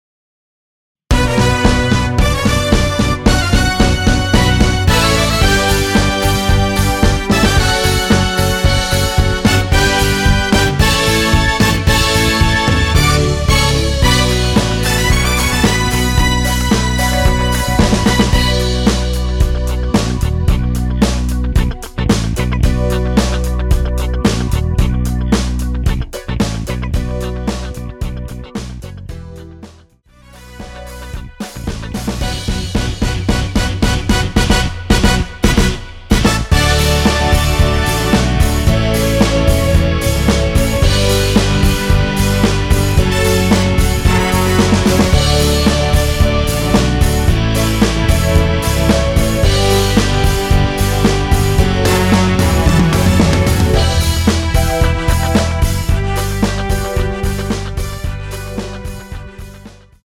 원키에서(-8)내린 MR입니다.
Bm
앞부분30초, 뒷부분30초씩 편집해서 올려 드리고 있습니다.
중간에 음이 끈어지고 다시 나오는 이유는